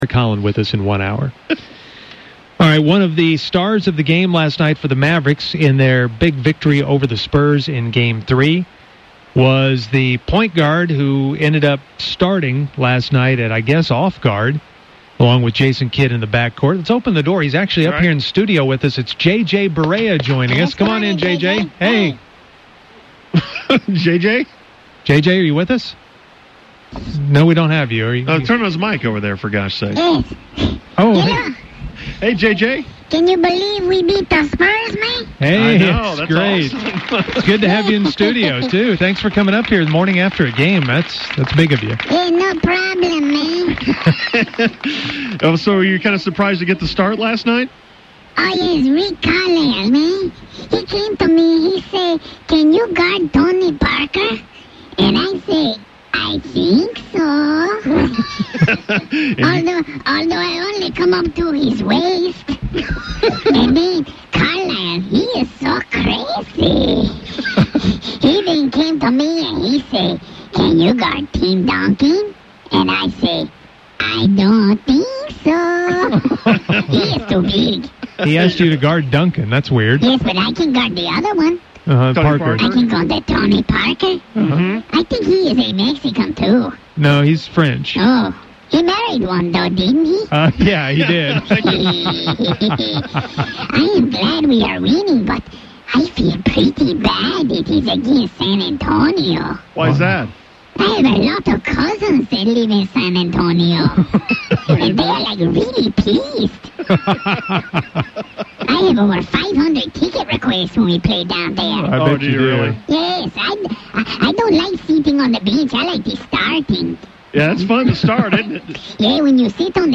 Umm, is this fake Barea? or the fake Barea done by the ticket mouse?